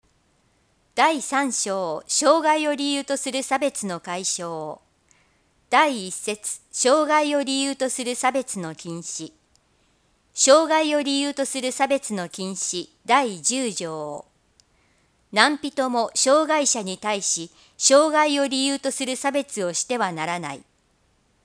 条例本文（音訳版）